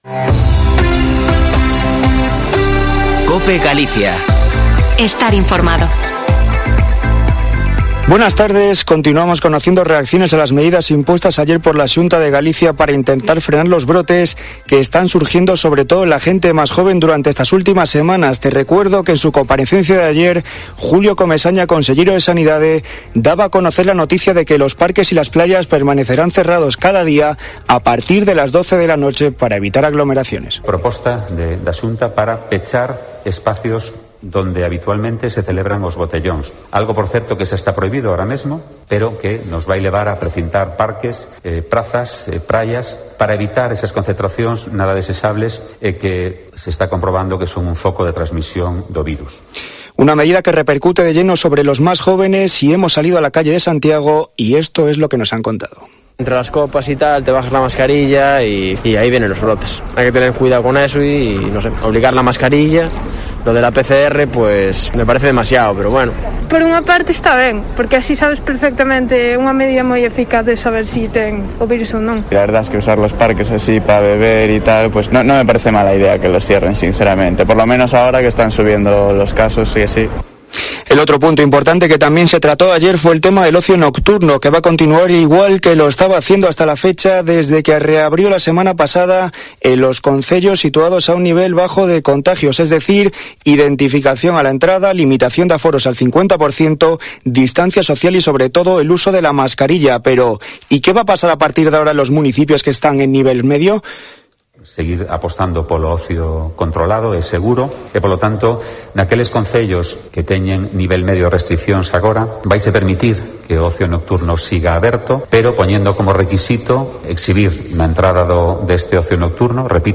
Informativo Mediodía en Cope Galicia 08/07/2021. De 14.48 a 14.58h